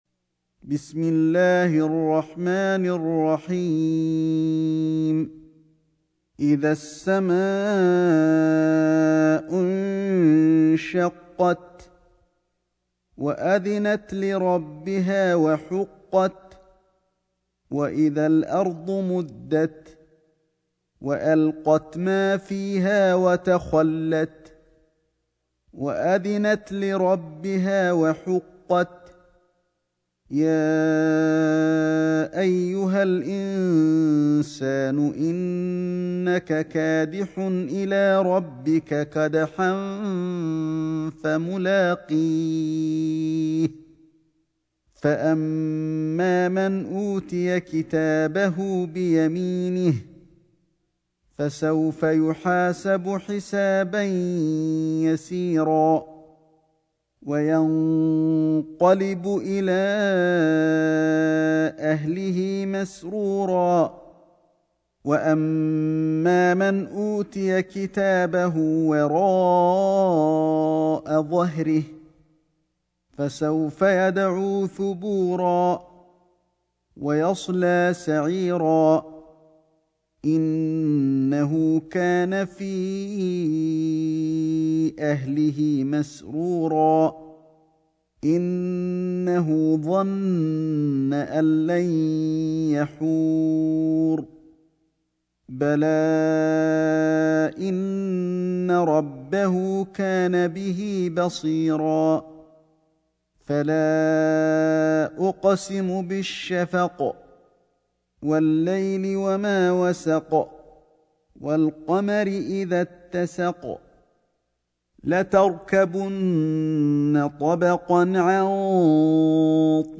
سورة الانشقاق > مصحف الشيخ علي الحذيفي ( رواية شعبة عن عاصم ) > المصحف - تلاوات الحرمين